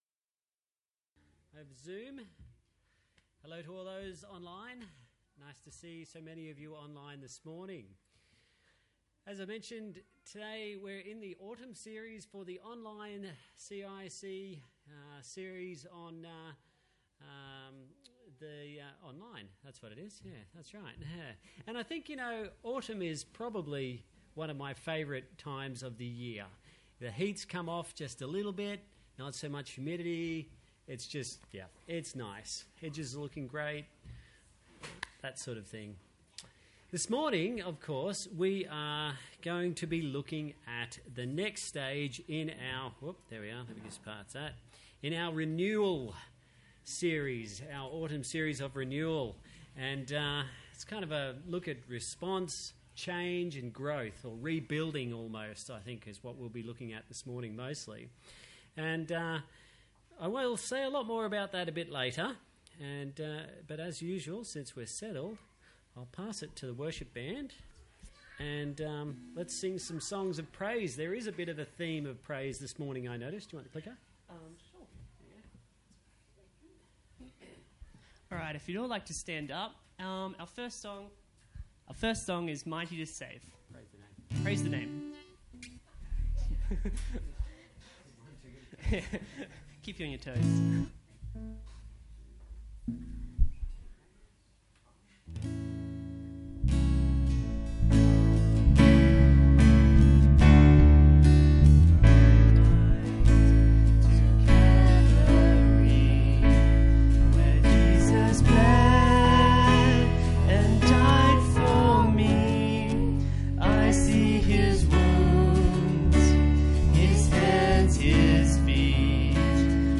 Service Type: Sunday Church